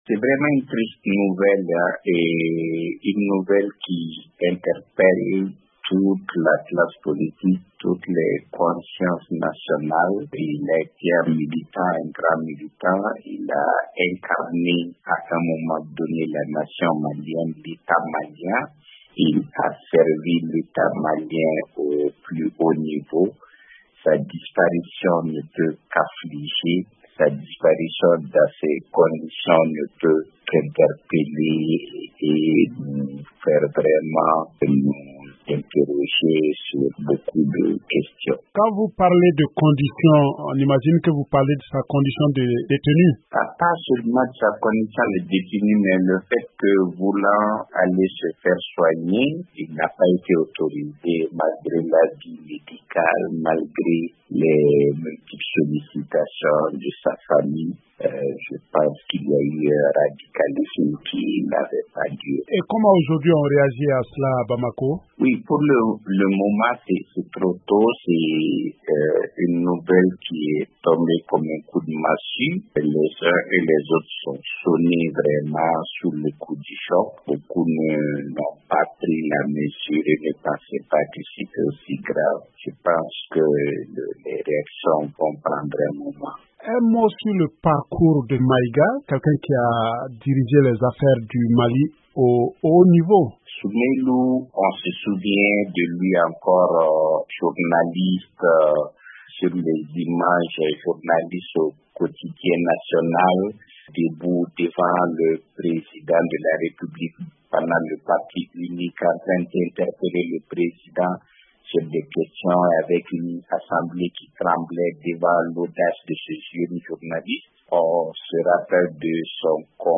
Il a été joint à Bamako